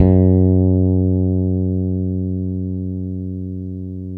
-MM BRYF F#3.wav